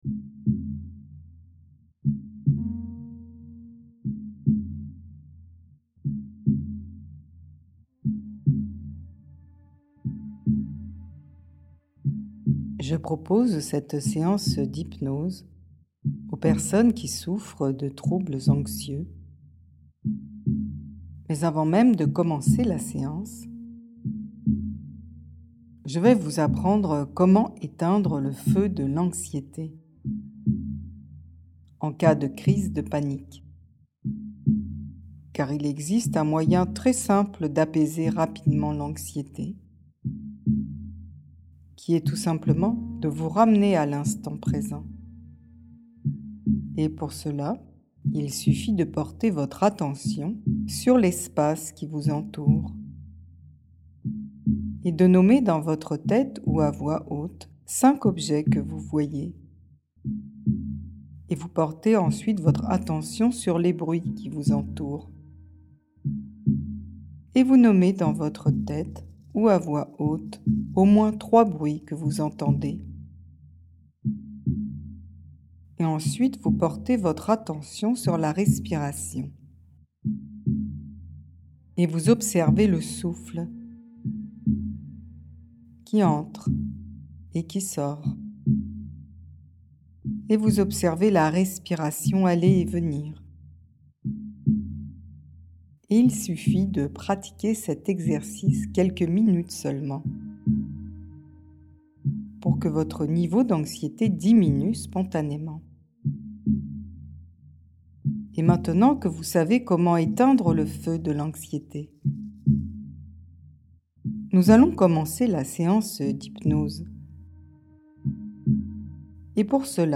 calmer l'anxiété - Autohypnoses mp3